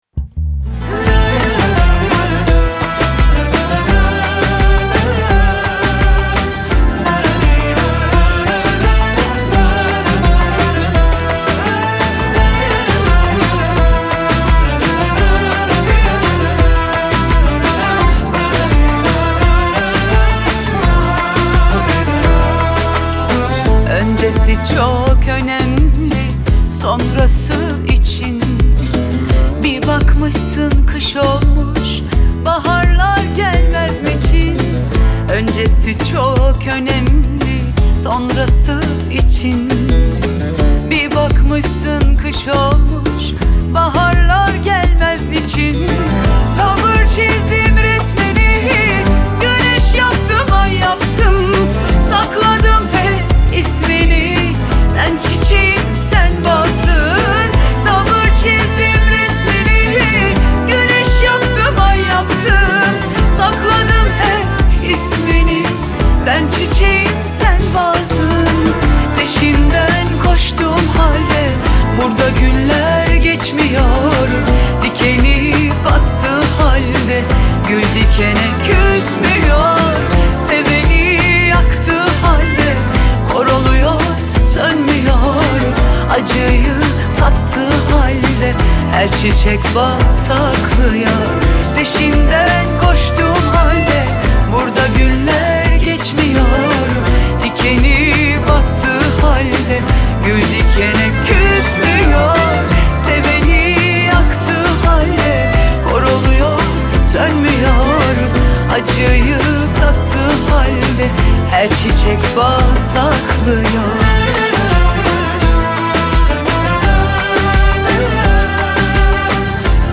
Tür: Türkçe / Pop